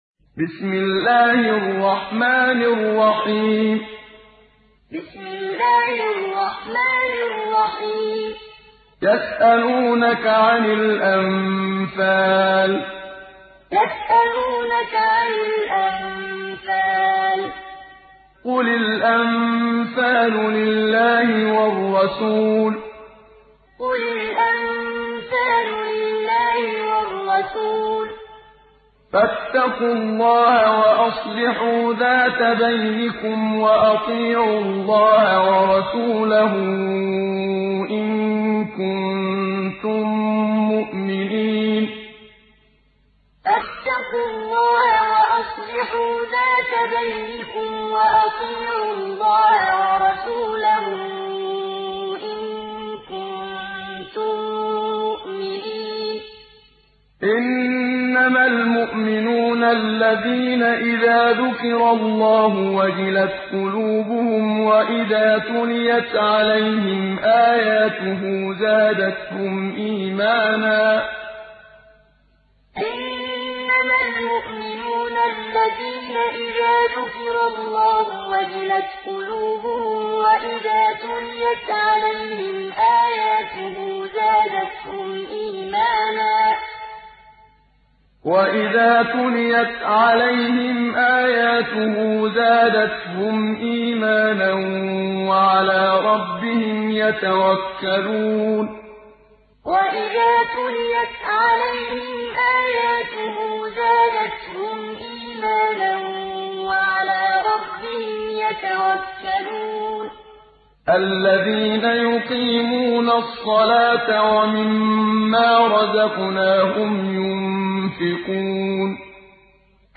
Surah Al Anfal mp3 Download Muhammad Siddiq Minshawi Muallim (Riwayat Hafs)